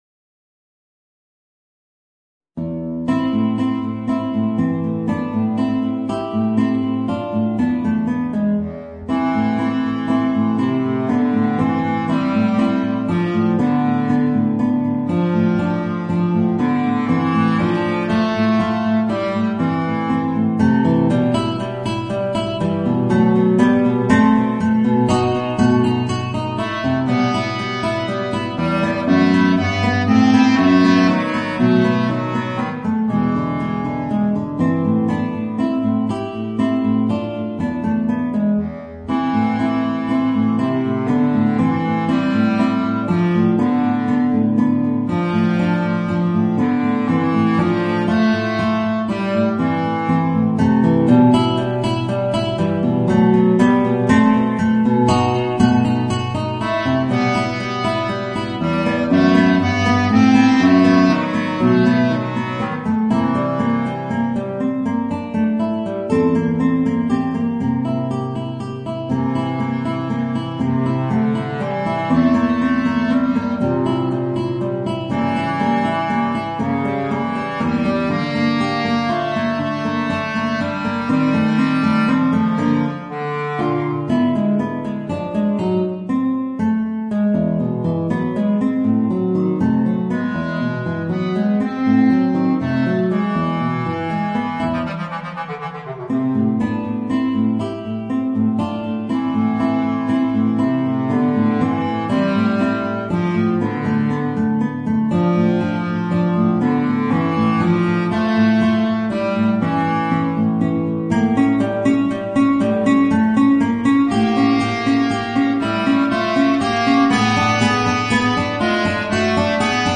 Bassklarinette & Gitarre